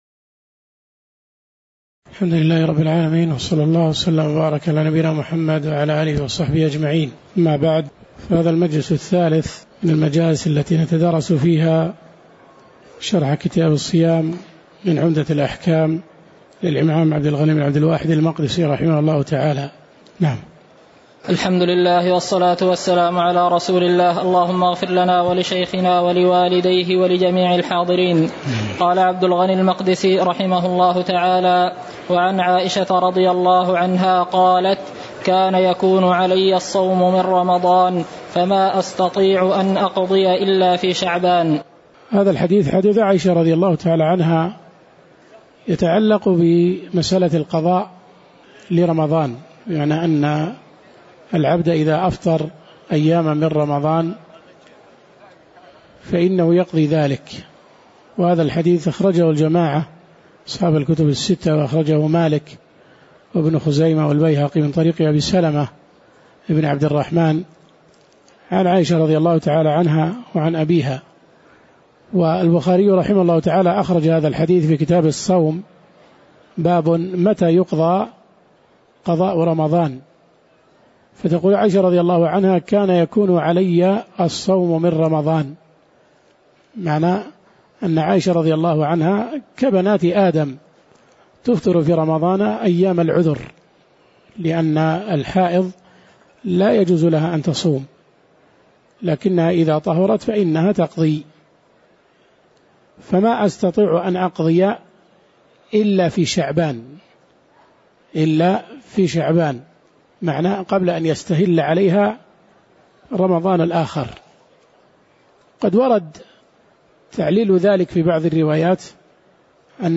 تاريخ النشر ١٤ شعبان ١٤٣٩ هـ المكان: المسجد النبوي الشيخ